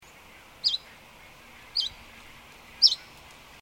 Sweeoo Chiffchaffs in Finland in 2011
Vermo, Espoo, Finland 19 September 2011, spectrogram below